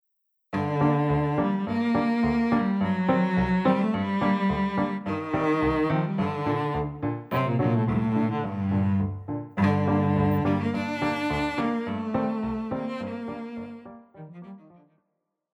Classical
Cello
Piano
Dance,Classics
Solo with accompaniment